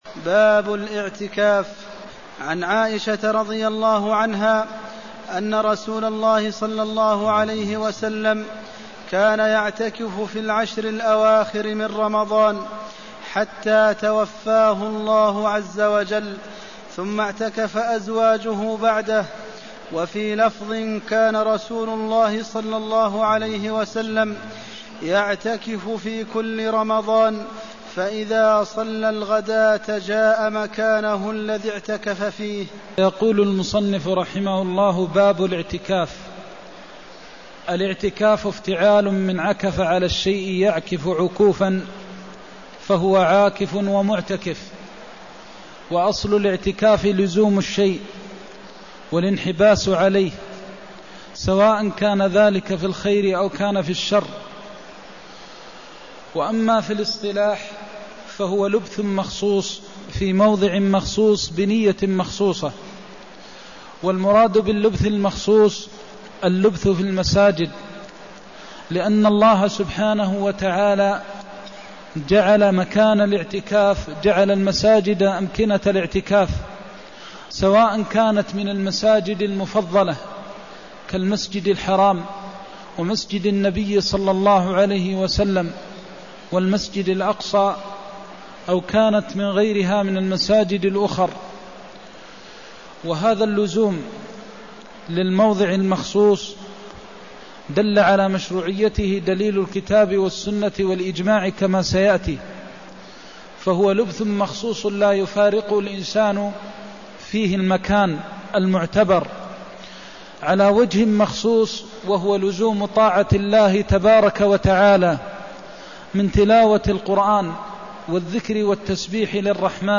المكان: المسجد النبوي الشيخ: فضيلة الشيخ د. محمد بن محمد المختار فضيلة الشيخ د. محمد بن محمد المختار كان يعتكف العشر الأوخر من رمضان (198)